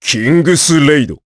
Gau-Vox_Kingsraid_jp.wav